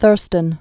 (thûrstən)